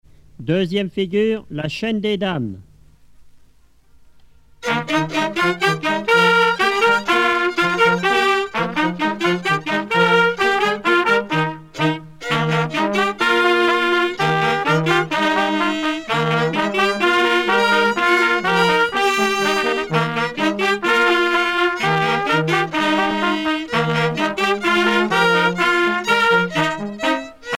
danse : quadrille : chaîne des dames
groupe folklorique
Pièce musicale éditée